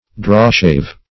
Drawshave \Draw"shave`\, n.
drawshave.mp3